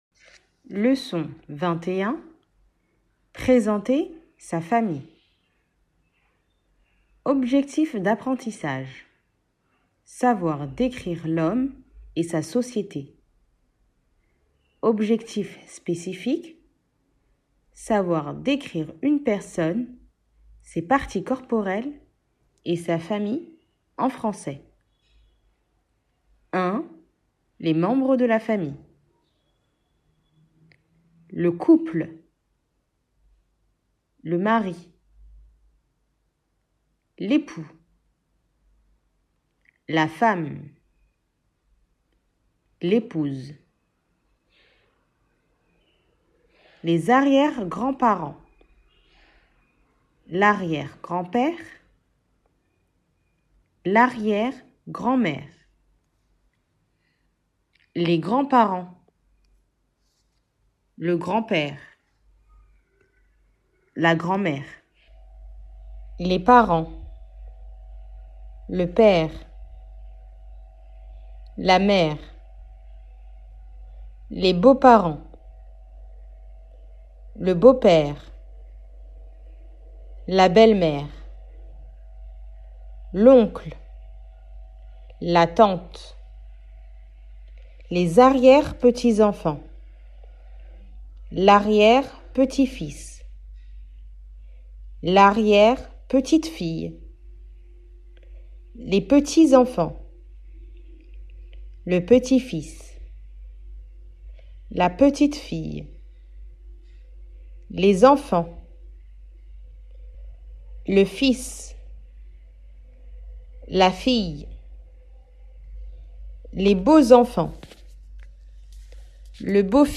Partie 1 : Leçon (1h)